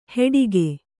♪ heḍige